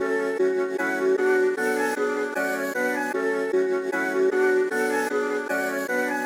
斩钉截铁的陷阱长笛
描述：用Omnisphere（莲花笛）制作的
Tag: 153 bpm Trap Loops Flute Loops 1.06 MB wav Key : Fm FL Studio